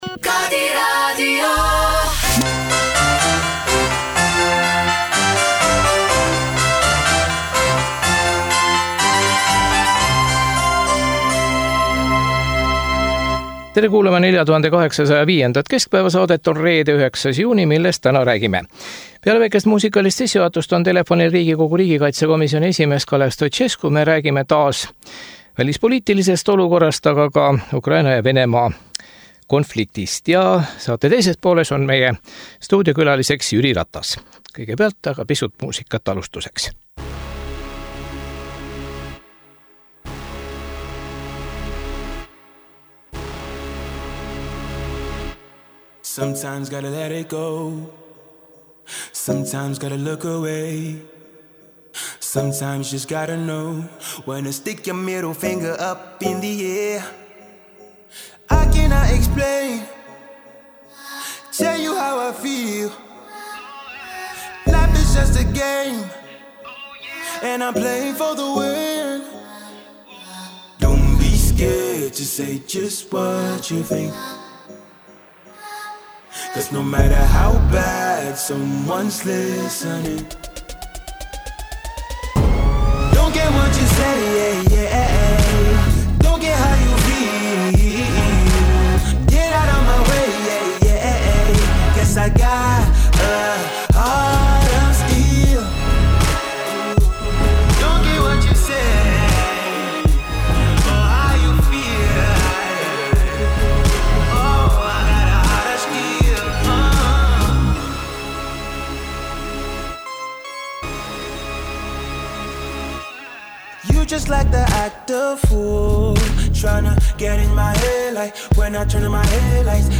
Telefoniintervjuu Riigikogu riigikaitse komisjoni esimehe Kalev Stoicescuga. Räägime jällegi Ukrainast (Kahhovka tamm, pealetungi algus), kuid ka NATO tippkohtumisest Vilnius 11.-12.07, ettevalmistamisest, Eesti plaanidest ja NATO uue peasekretäri valimisest
Stuudiokülaline on Riigikogu teine aseesimees Jüri Ratas.